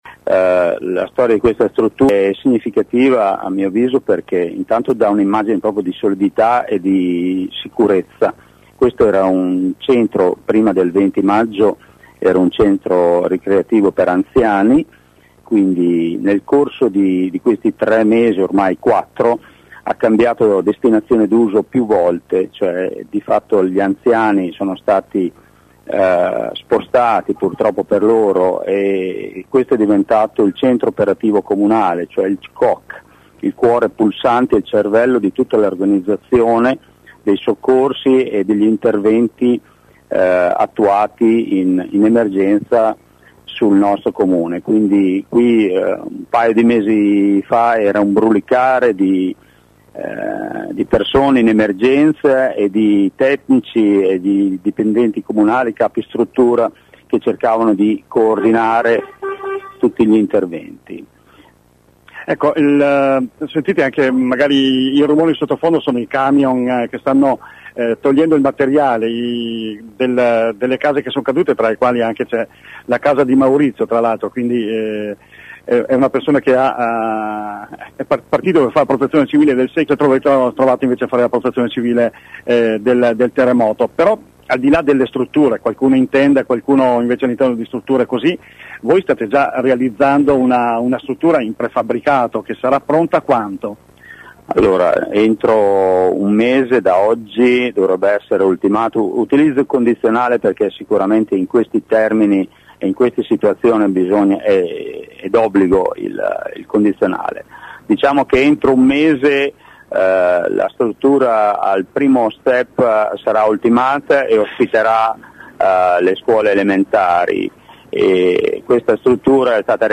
Ecco alcune delle voci raccolte questa mattina dai nostri inviati.